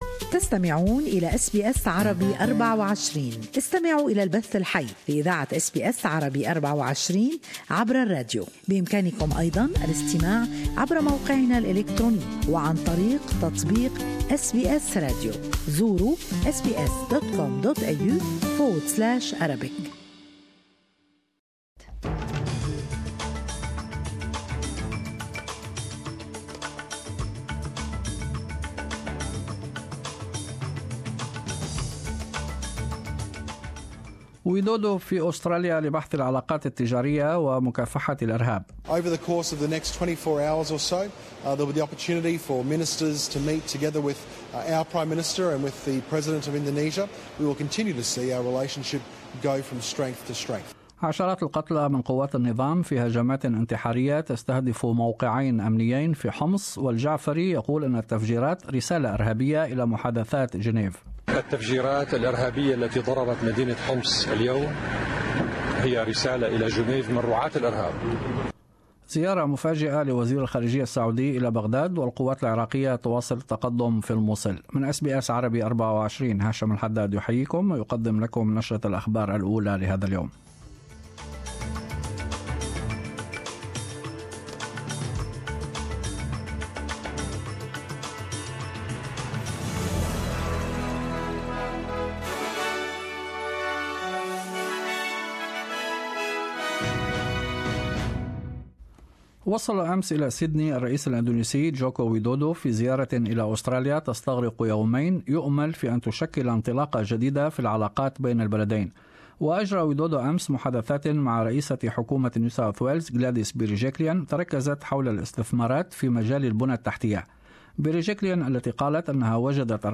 News Bulletin 26 Feb 2017